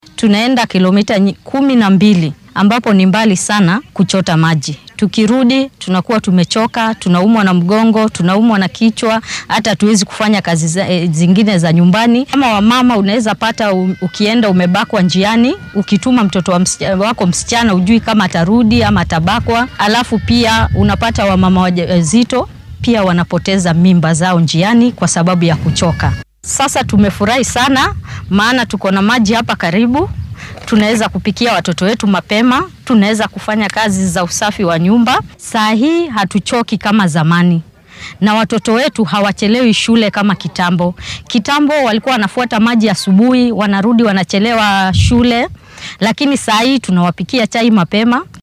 Mid ka mid ah hooyooyinka tuuladaasi ayaa sheegtay inay horay dhowr kiilomitir u lugeyn jireen inay biyo soo dhaansadaan taasoo caqabad ku ahayd.
Mid-ka-mid-ah-hooyooyinka-Marsabit.mp3